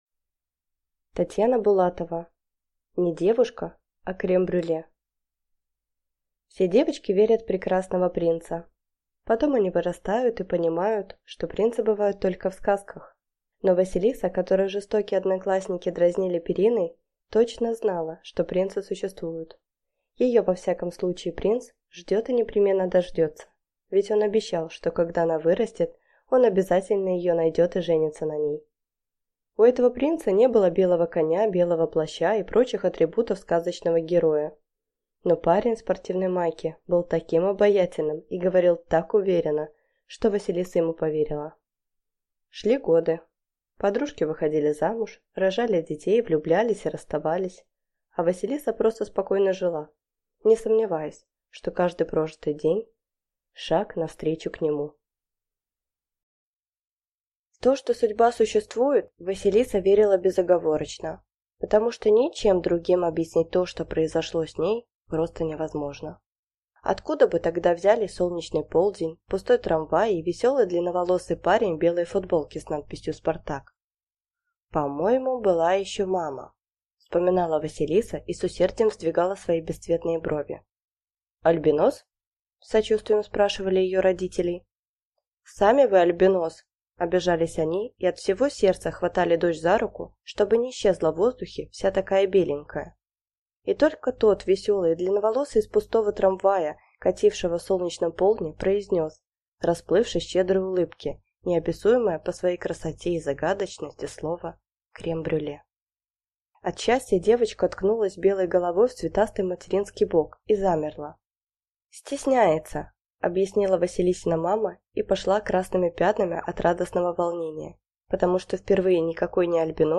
Аудиокнига Не девушка, а крем-брюле | Библиотека аудиокниг